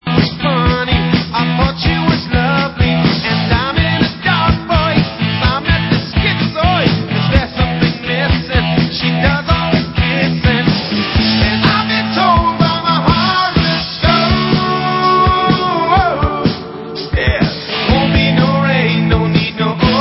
• styl: Glam